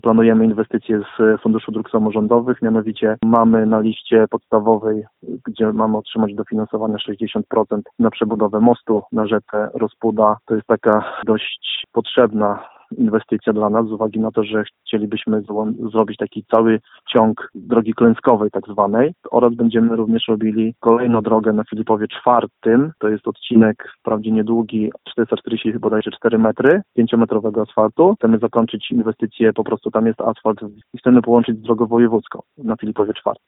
O szczegółach mówi Radiu 5 Tomasz Rogowski, wójt gminy.